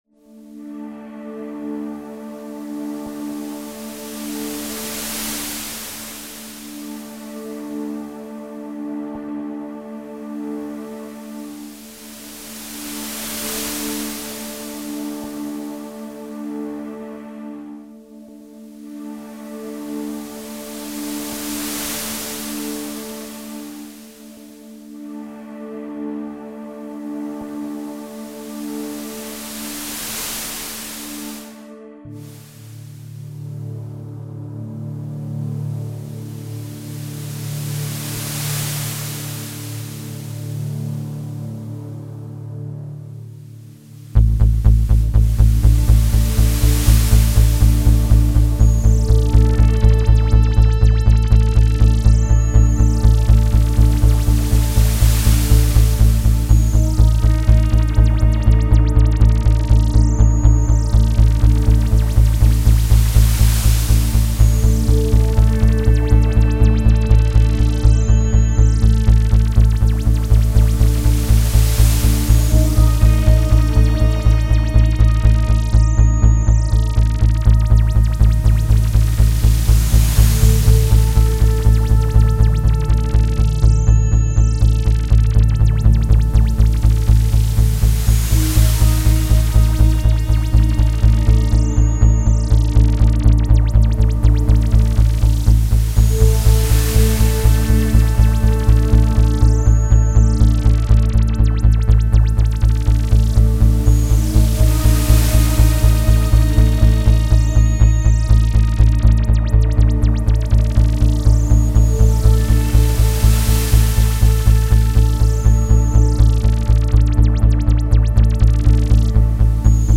File under: Avantgarde
pure electronic delights